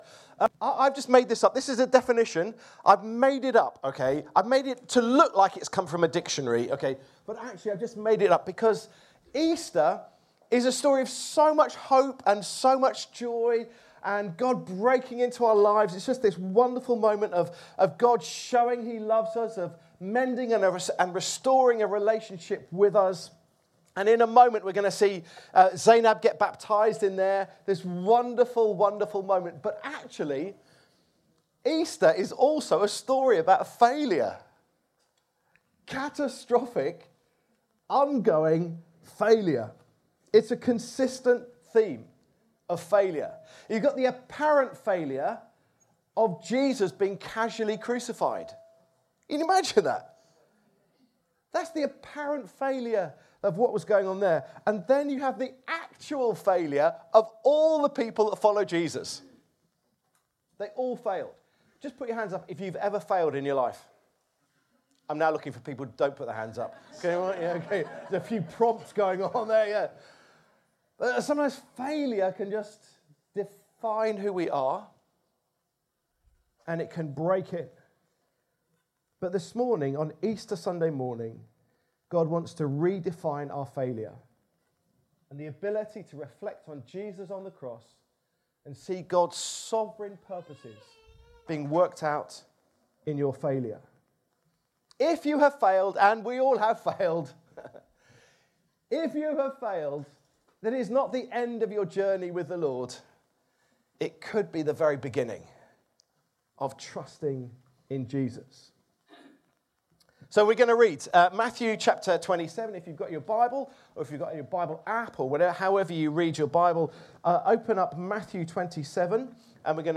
Download Easter Sunday | Sermons at Trinity Church